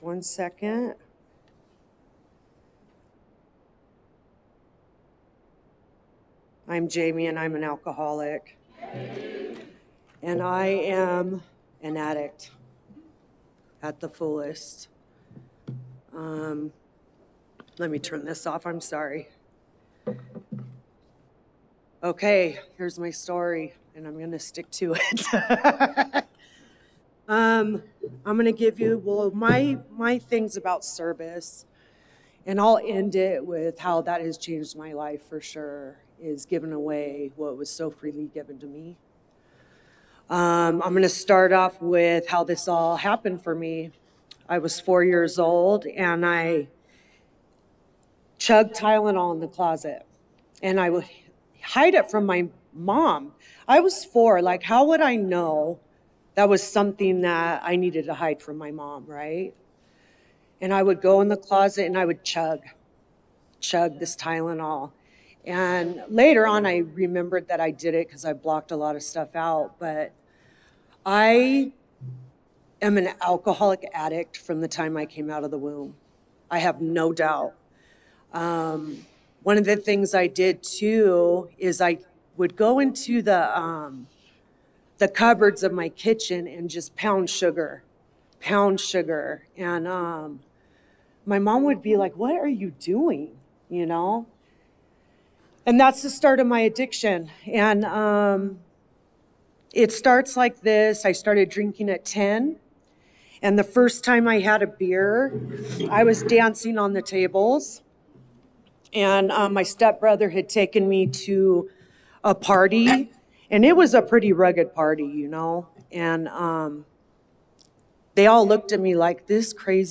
33rd Indian Wells Valley AA Roundup with Al-Anon and NA
Service &#8211